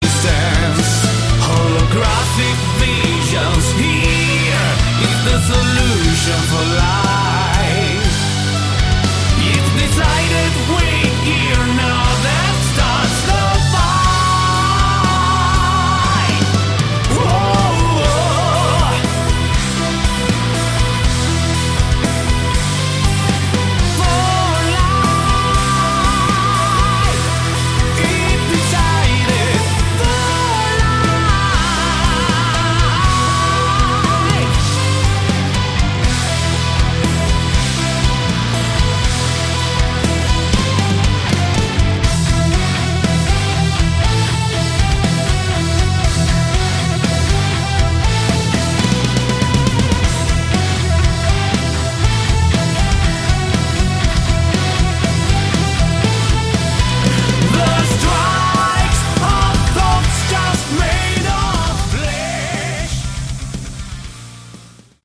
Category: Melodic Prog Rock
vocals
guitars
keyboards
bass
drums